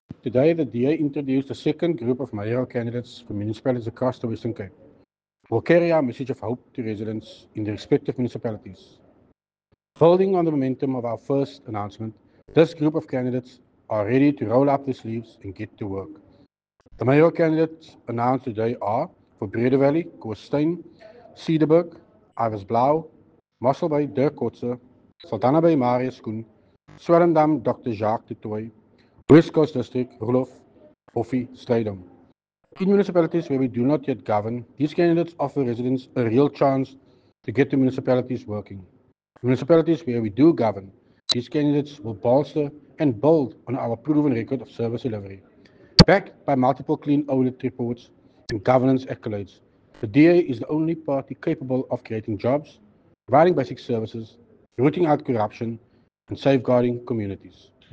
Issued by Tertuis Simmers – DA Western Cape Leader
soundbite by Tertuis Simmers